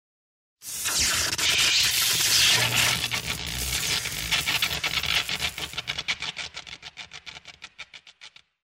Звуки проводов
Электрические шумы проводки